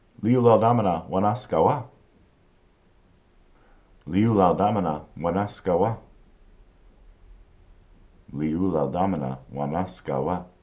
li-oo-lal-da-mana   wa-nas-kawa